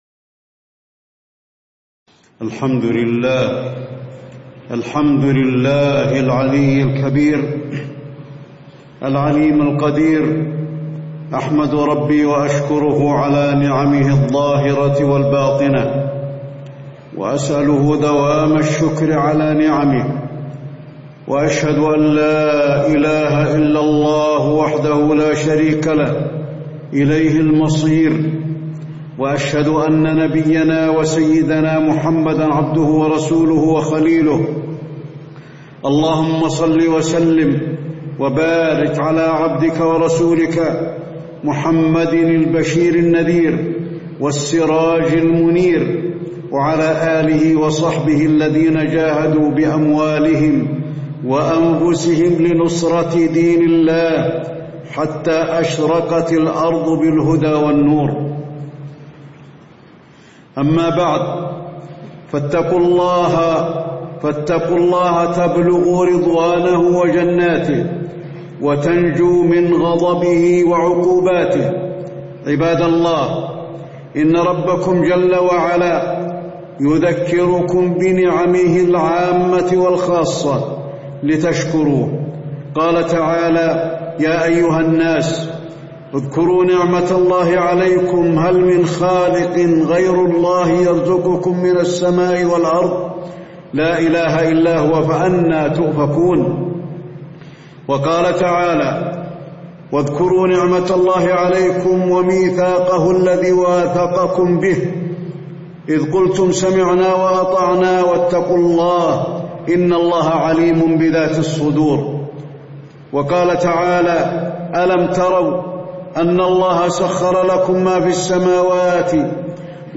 تاريخ النشر ٢٠ شعبان ١٤٣٧ هـ المكان: المسجد النبوي الشيخ: فضيلة الشيخ د. علي بن عبدالرحمن الحذيفي فضيلة الشيخ د. علي بن عبدالرحمن الحذيفي فضل الشكر لله تعالى